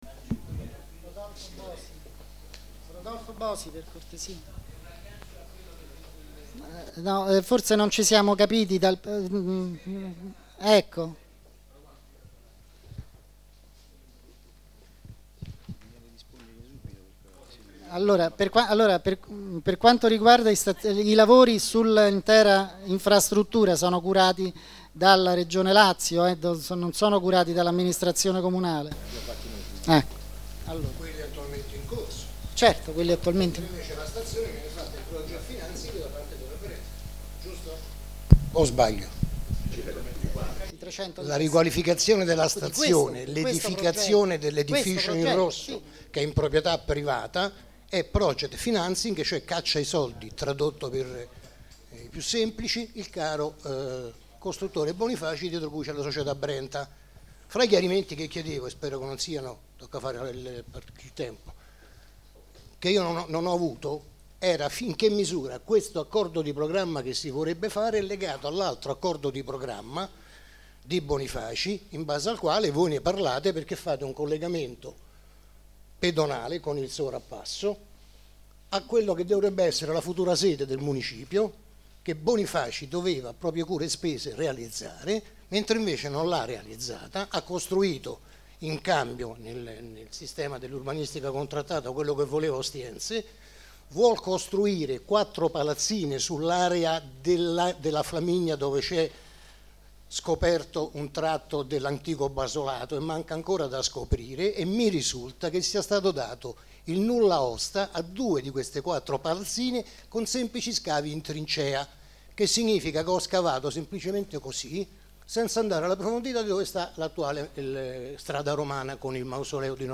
Registrazione integrale dell'incontro svoltosi il giorno 8 maggio 2012 presso
la sala consiliare del Municipio Roma 20